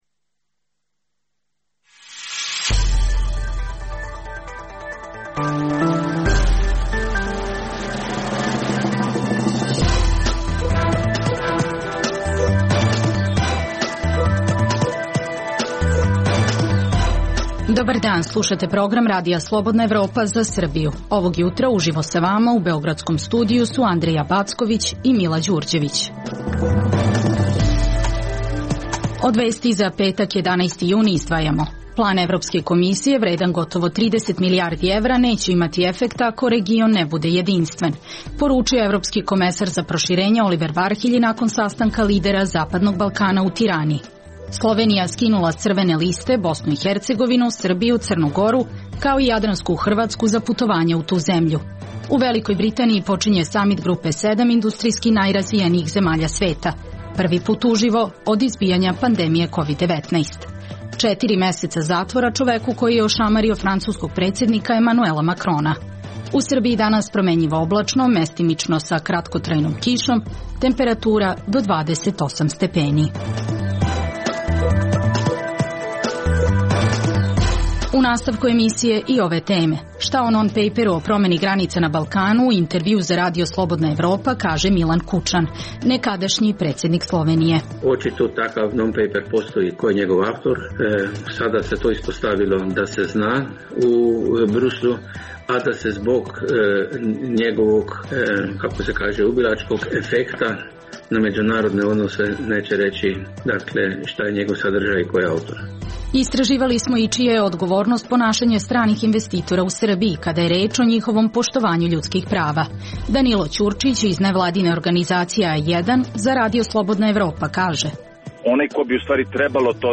Šta o non-pejperu o promeni granica na Balkanu, u intervjuu za RSE kaže Milan Kučan, nekadašnji predsednik Slovenije. Čija je odgovornost ponašanje stranih investitora u Srbiji kada je reč o poštovanju ljudskih prava. U Skupštini Srbije izglasan predlog Vlade za izmenu Ustava u delu o pravosuđu.